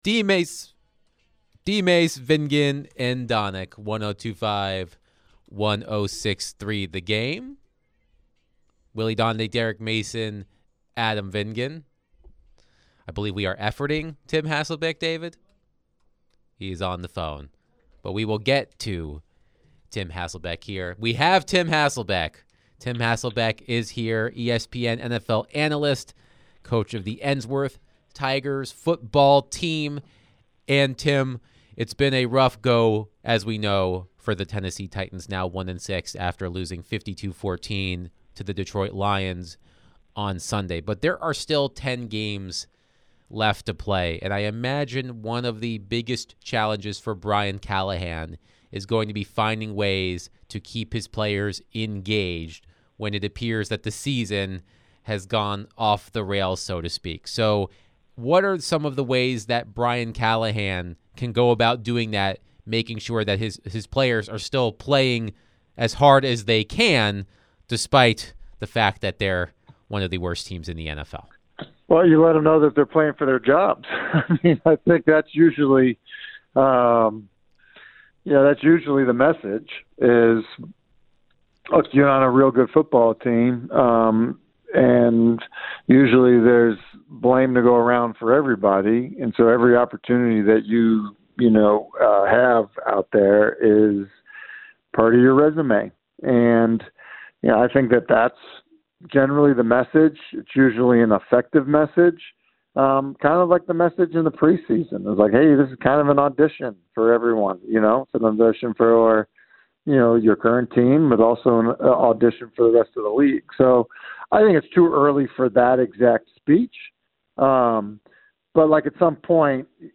ESPN NFL Analyst Tim Hasselbeck joined the show to discuss the Titans' whopping loss to the Lions on Sunday. What does Tim think is the Titans' biggest problem?